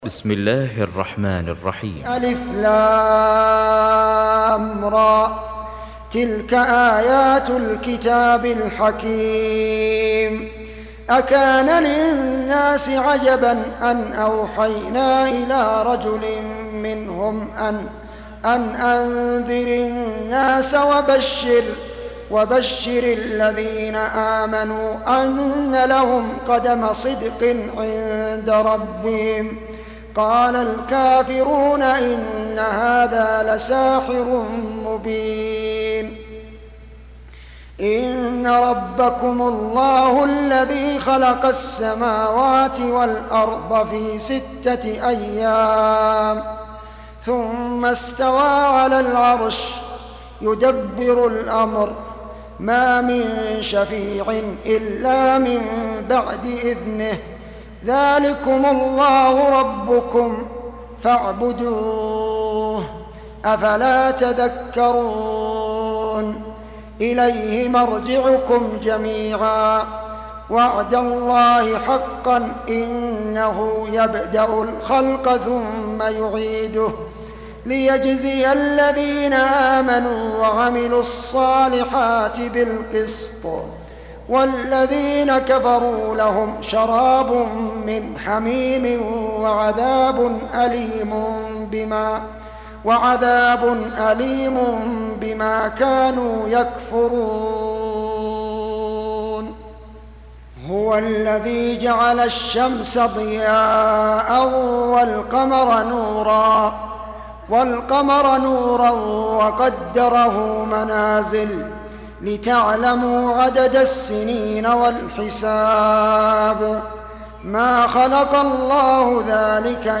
Surah Repeating تكرار السورة Download Surah حمّل السورة Reciting Murattalah Audio for 10. Surah Y�nus سورة يونس N.B *Surah Includes Al-Basmalah Reciters Sequents تتابع التلاوات Reciters Repeats تكرار التلاوات